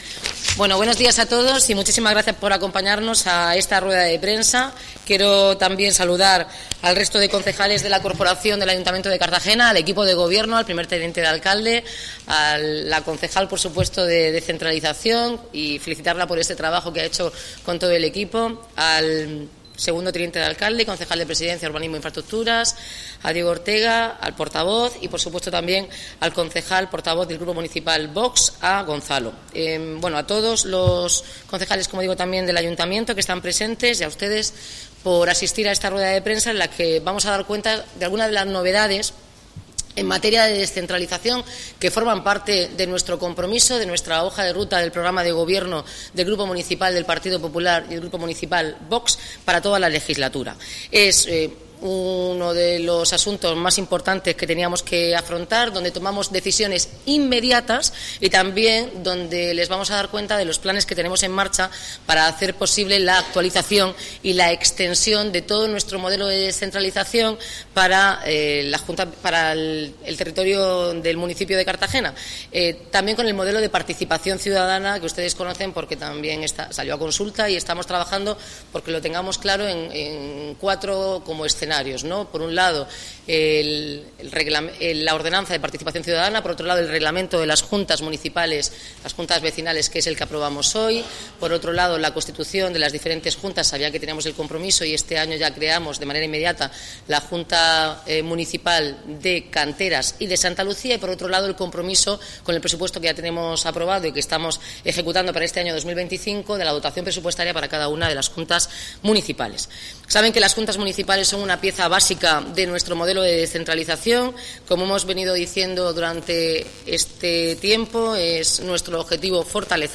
Audio: Declaraciones de la alcaldesa, Noelia Arroyo, en la presentaci�n del mapa de Juntas Municipales (MP3 - 8,30 MB)